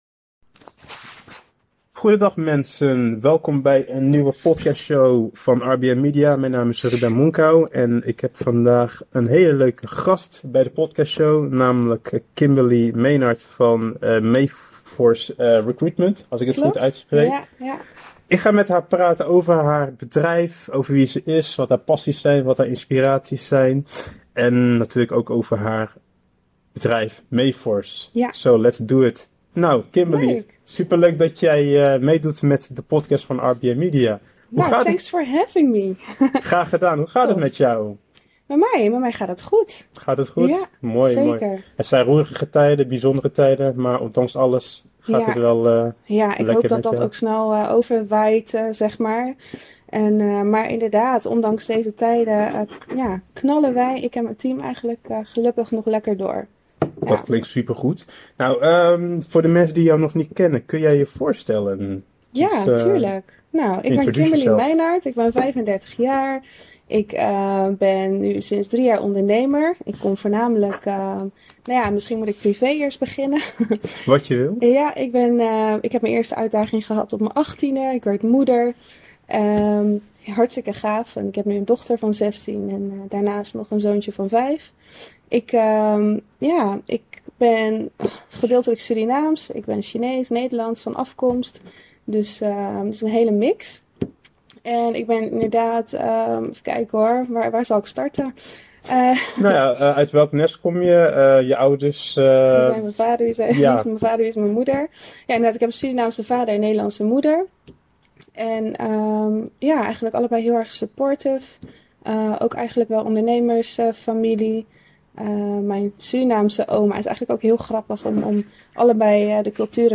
In deze podcast kun je luisteren naar een inspirerend gesprek